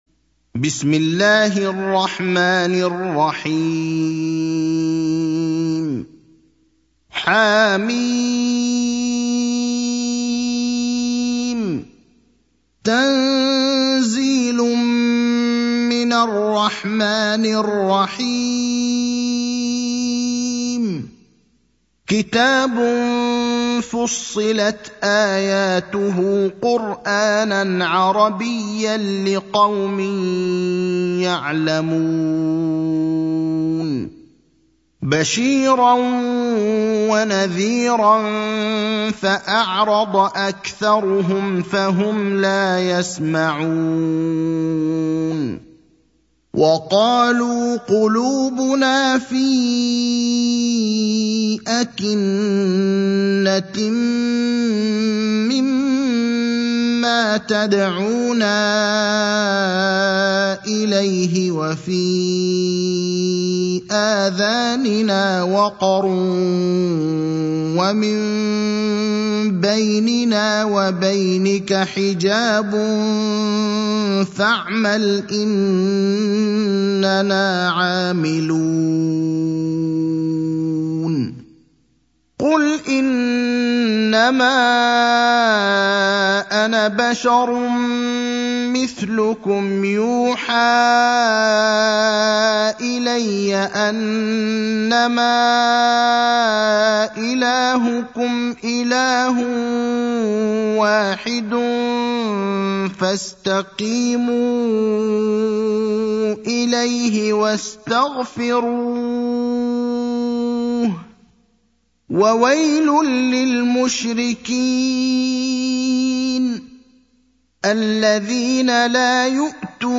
المكان: المسجد النبوي الشيخ: فضيلة الشيخ إبراهيم الأخضر فضيلة الشيخ إبراهيم الأخضر فصلت (41)